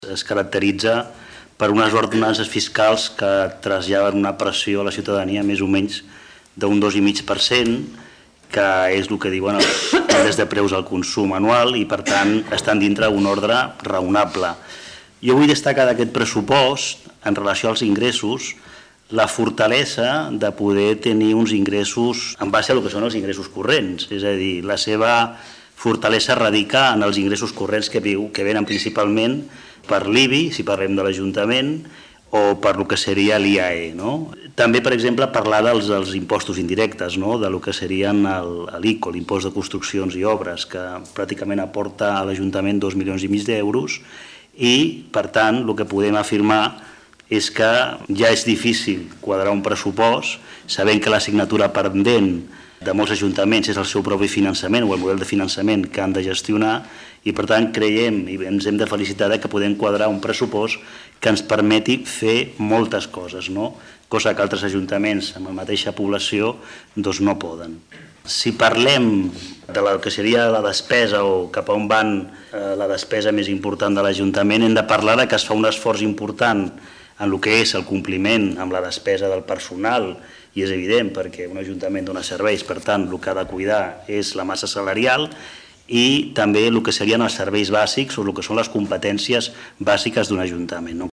Javier González, regidor de Transició Digital i Sostenible
Ple-Municipal-Desembre-05.-Javier-Gonzalez.mp3